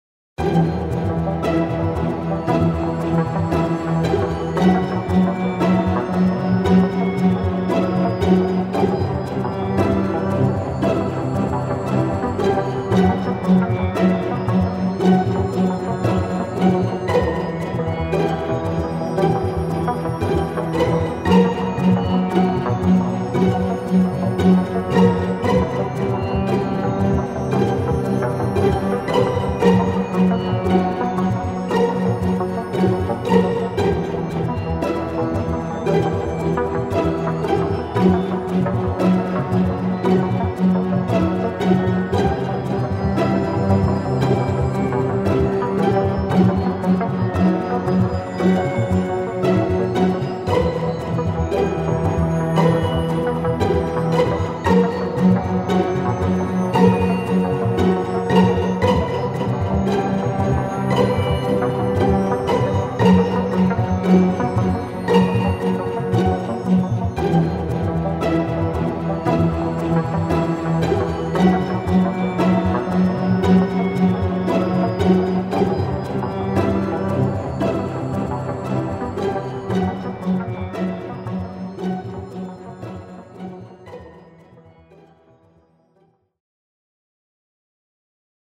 Ambient rhythmic hybrid track for RPG and fantasy.